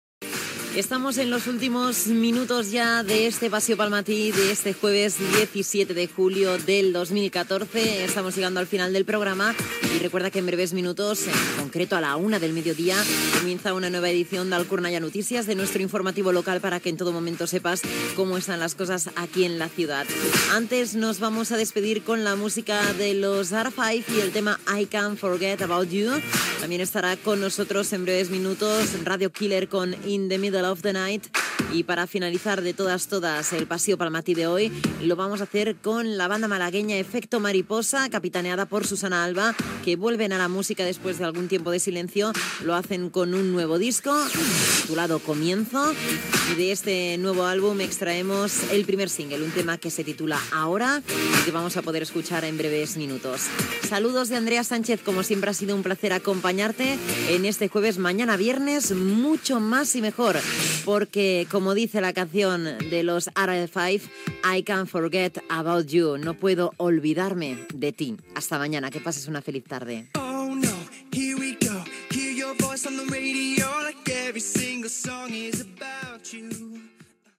Temes musicals que tancaran el programa i comiat
Musical